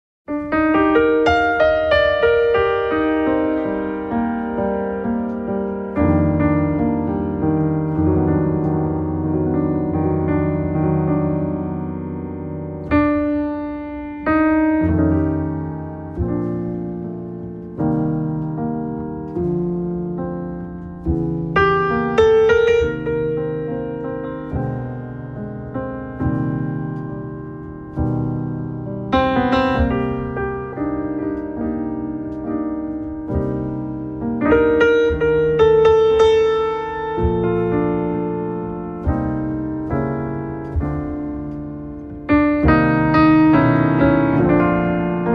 Recorded At Sony Music Studios In Tokyo On January 23, 2013.